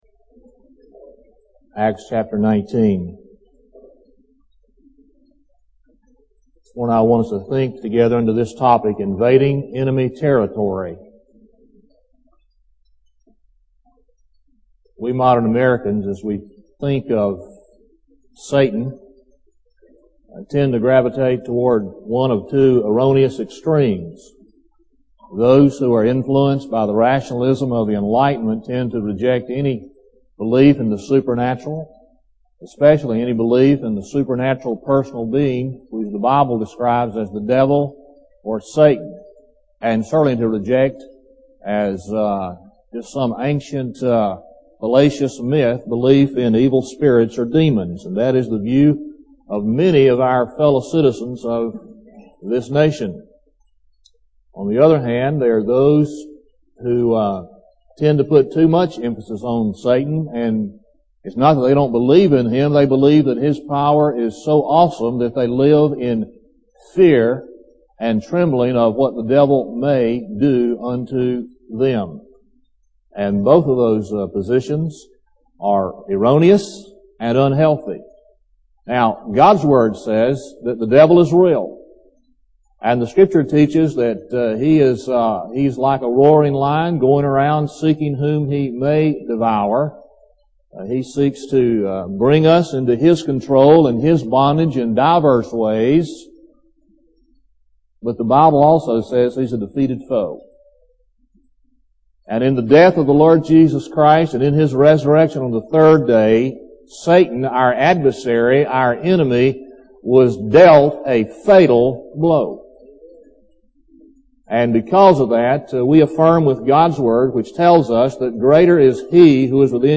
Lakeview Baptist Church - Auburn, Alabama